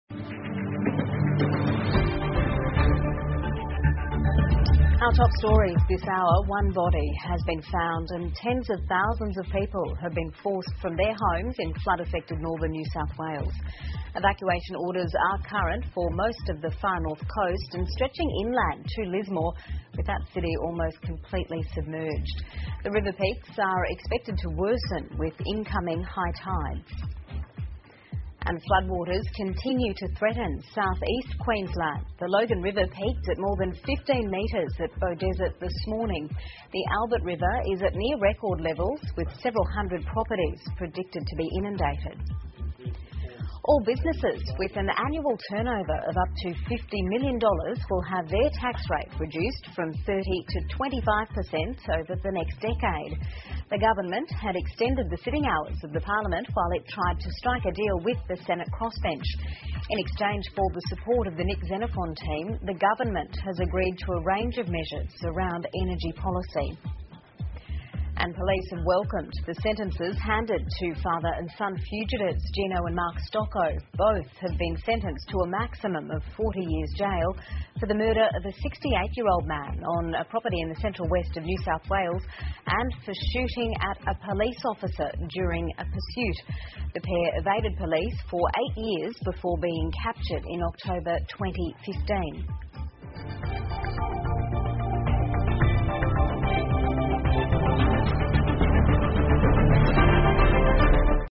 澳洲新闻 (ABC新闻快递) 新南威尔士州洪水肆虐 年营业额5千万以下澳企获减税 听力文件下载—在线英语听力室